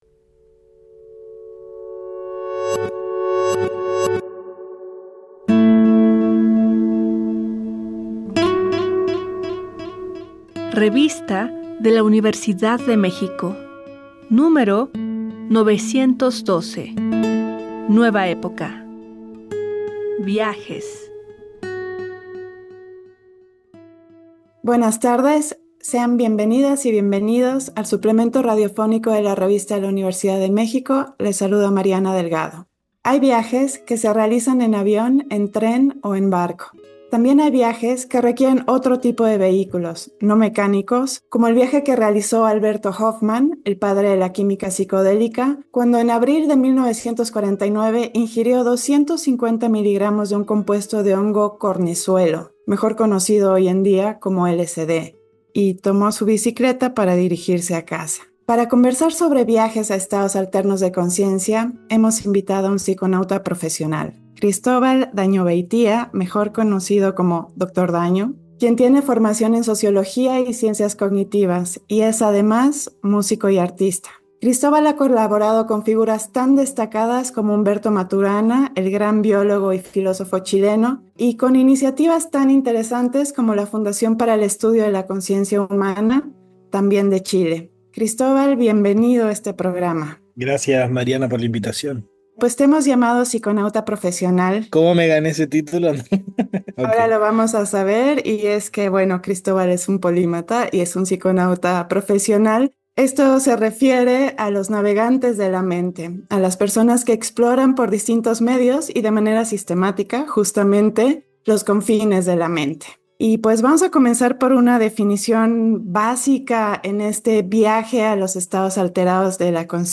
Fue transmitido el jueves 12 de septiembre de 2024 por el 96.1 FM.